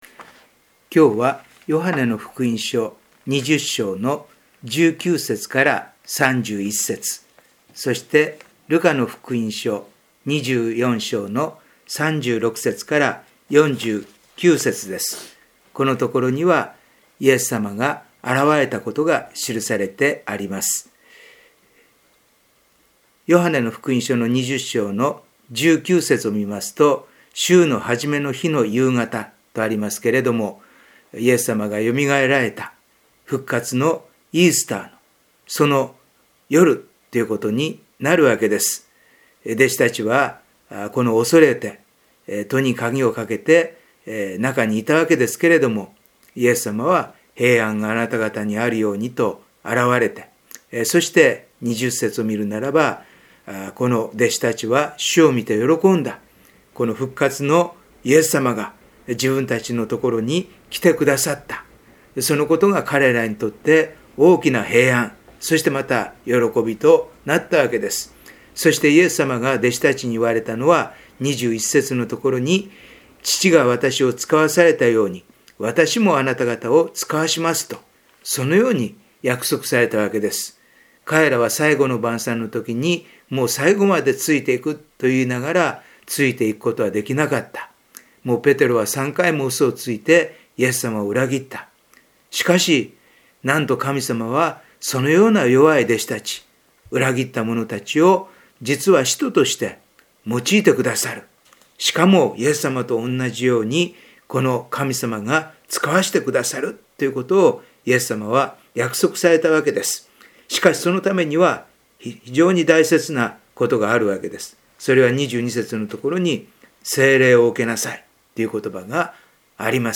4月のデボーションメッセージ